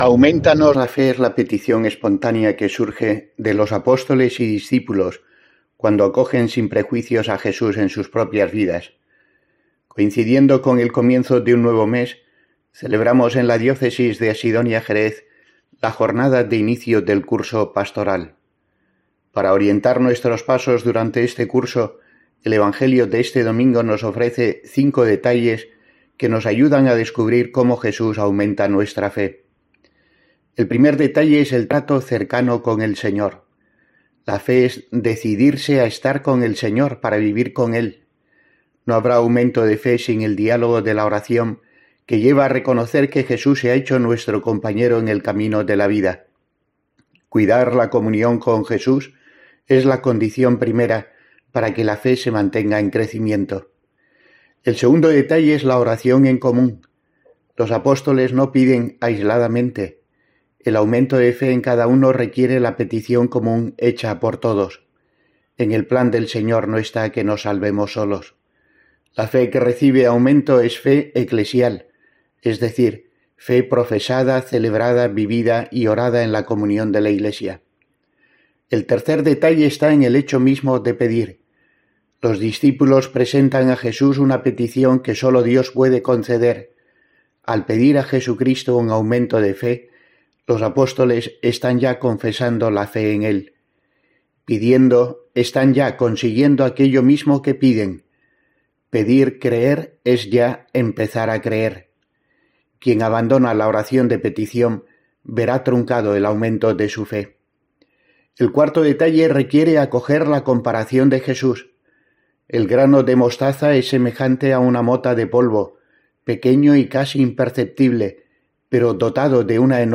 Escucha aquí la reflexión semanal de monseñor José Rico Pavés, obispo de Asidonia-Jerez 30-09-22